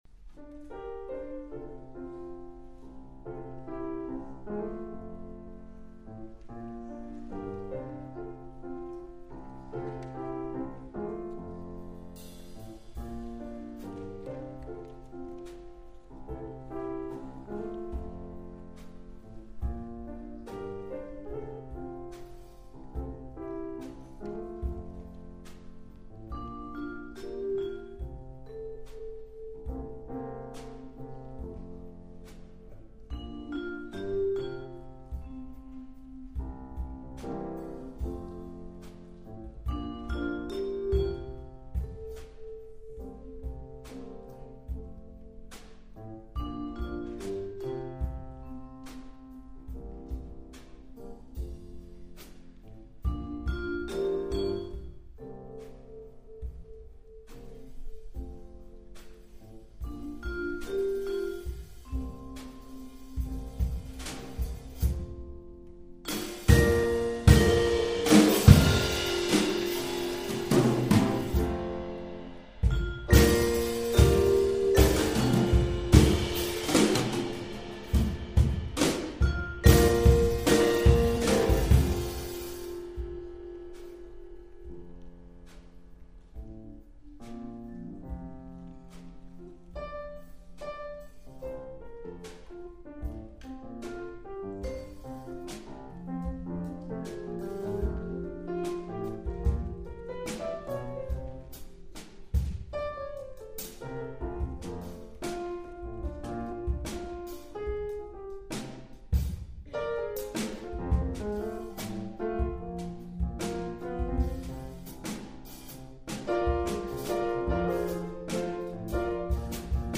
Jazz Ensemble recordings :: Music :: Swarthmore College